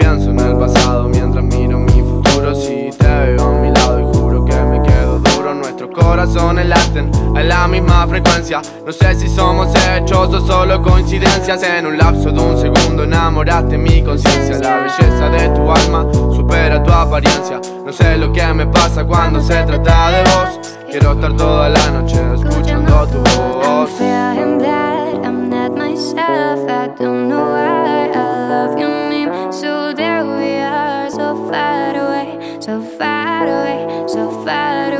Жанр: Латиноамериканская музыка / Рэп и хип-хоп
# Latin Rap